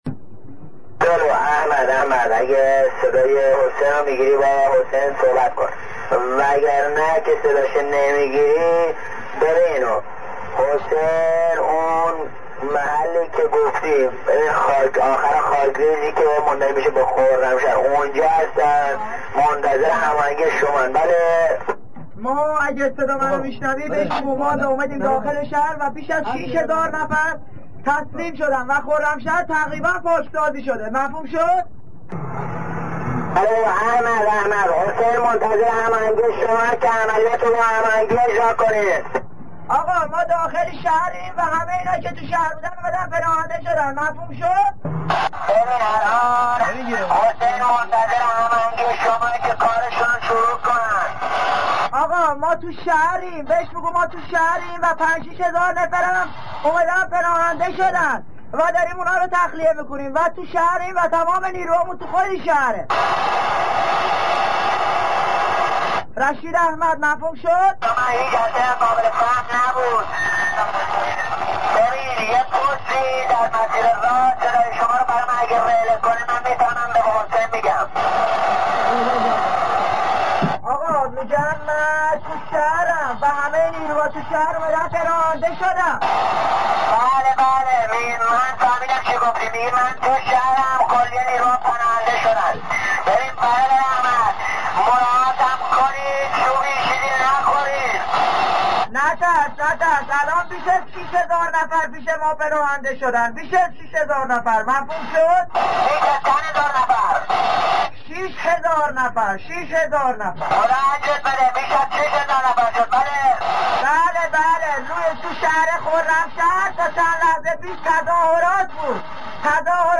مکالمه بی‌سیم سرداران شهید خرازی و احمد کاظمی با سردار رشید در لحظه آزادی خرمشهر؛رشید رشید احمد: رشید جان بله بله تو شهر خرمشهر تا چند لحظه پیش تظاهرات بود،